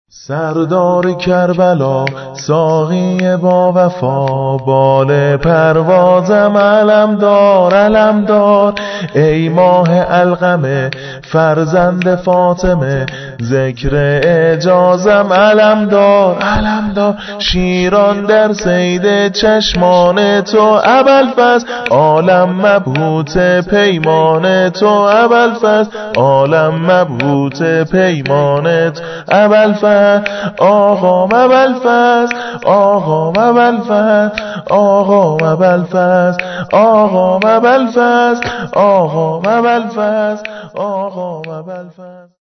-سرود ميلاد-
حرم رضوي